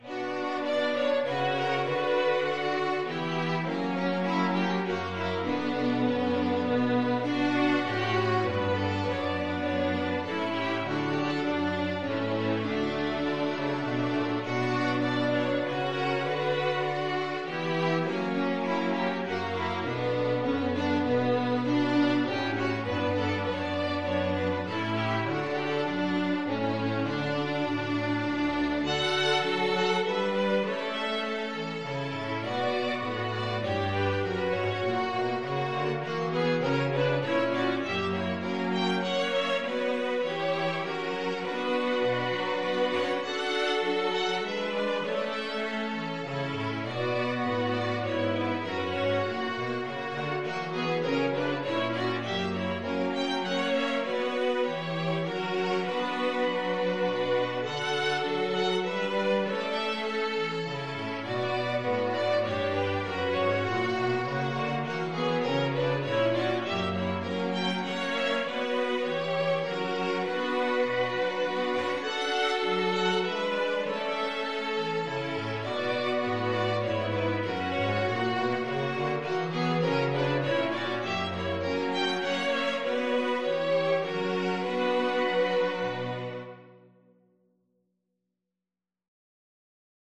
Violin 1Violin 2ViolaCello
3/4 (View more 3/4 Music)
Classical (View more Classical String Quartet Music)